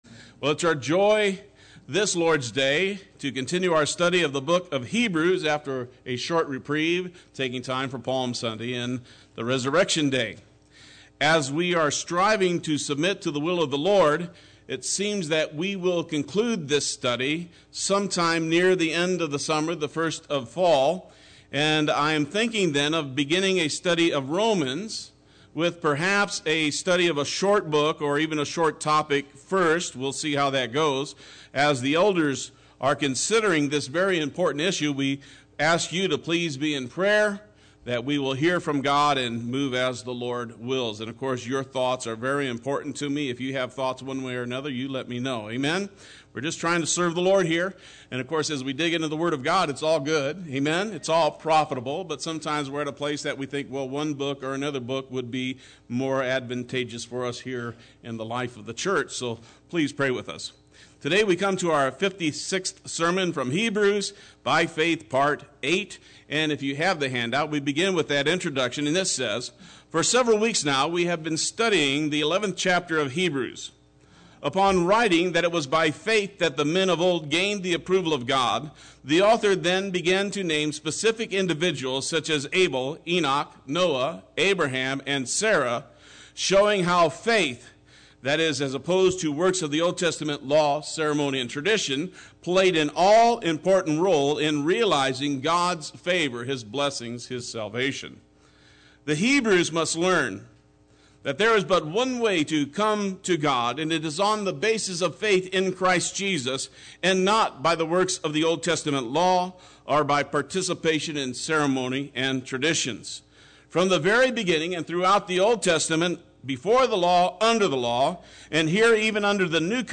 Play Sermon Get HCF Teaching Automatically.
Part 8 Sunday School